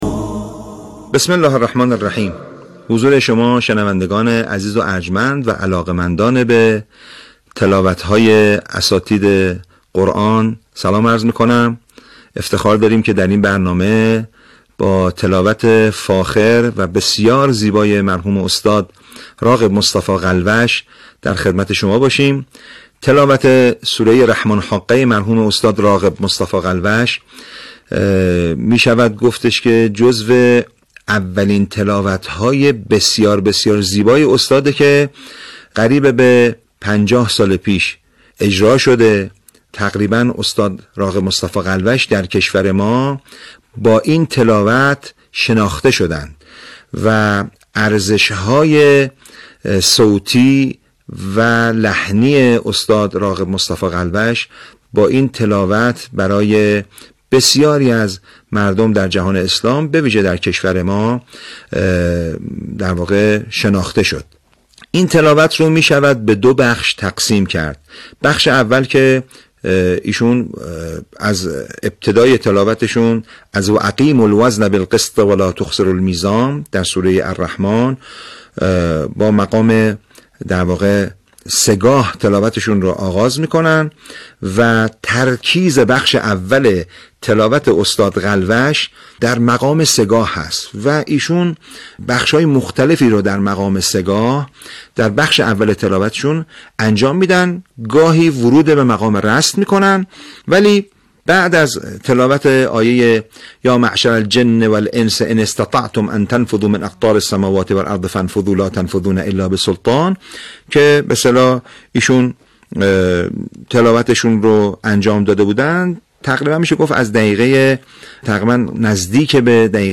فایل صوتی تلاوت سوره حاقه در قاهره مسجد امام حسین(ع) که در دهه 60 میلادی اجرا شده است.
مقام رست را با فراز و نشیب‌های خاصی در کلمات برخی از آیات اجرا می‌کند.
استاد غلوش با این‌که صوت بسیار فاخر و یک جلال و وقار با طنین زیبا در صدای وی وجود دارد، اما همچنان سعی در ادای فصیح کلمات و رعایت احکام تجویدی دارد. در عین حال سعی کرده است از توانایی‌های خاصش در انعطاف صوتی و تحریرها برای زیباتر کردن تلاوت، هرچه بیشتر استفاده کند.